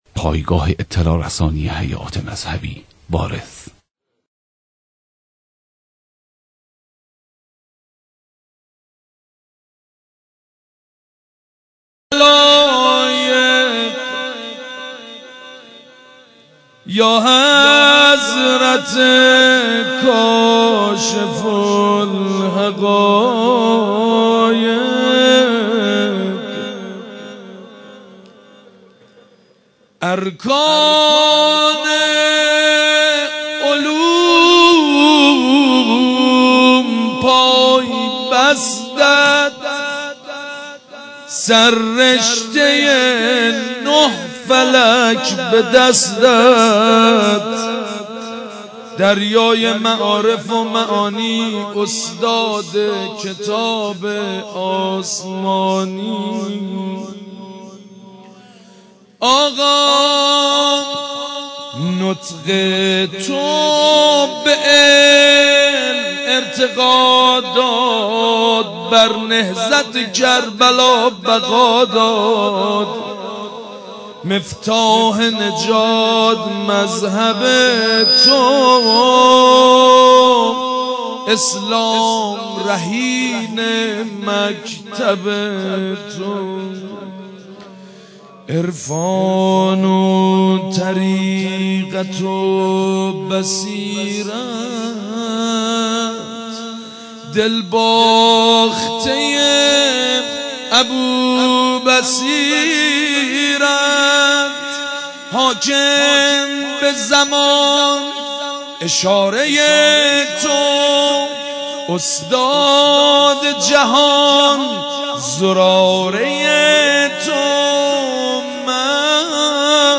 مداحی حاج سید مجید بنی فاطمه به مناسبت شهادت امام صادق(ع)